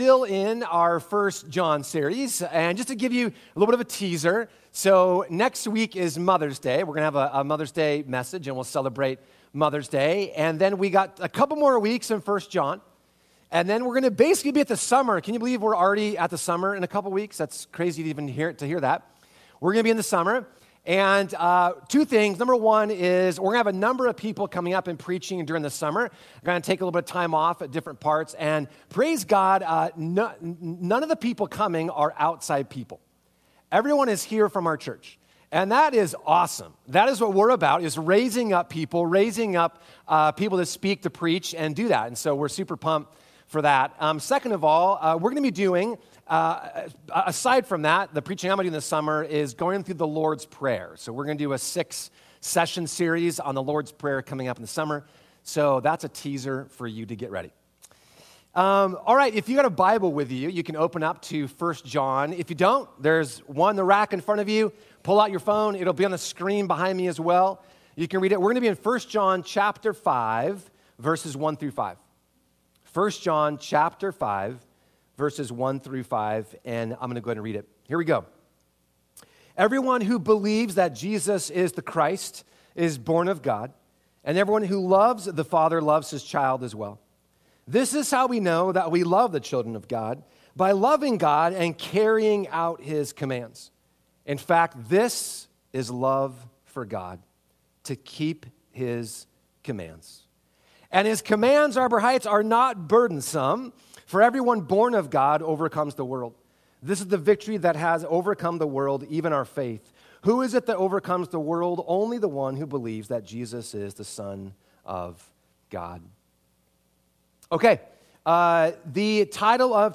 Sermons | Arbor Heights Community Church